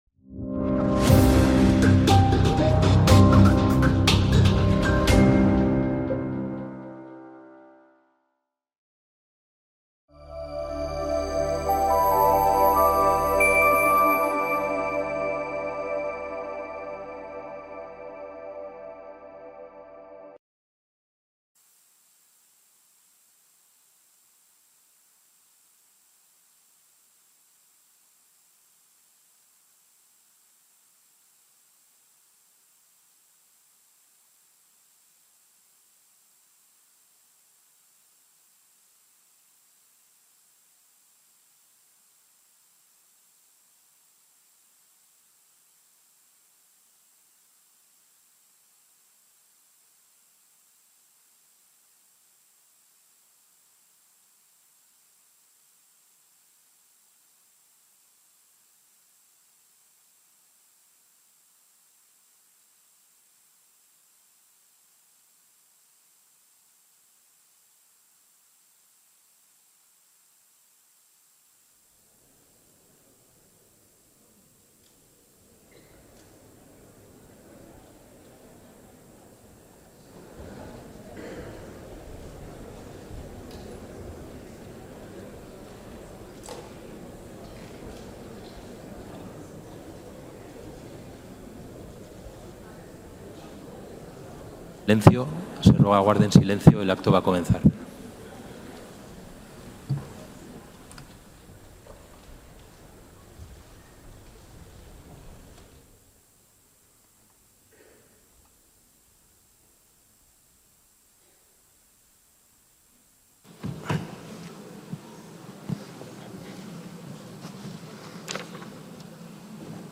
Acto de Estado en Memoria de las Víctimas del Holocausto (Senado, Madrid, 27/1/2025)
ACTOS EN DIRECTO - Por décimo año consecutivo, el Senado de España ha acogido el acto solemne de Estado en Recuerdo a las víctimas del Holocausto en el Día Oficial de la Memoria del Holocausto y Prevención de los Crímenes contra la Humanidad, institucionalizado en 2005 por la Asamblea General de la ONU y el Parlamento Europeo.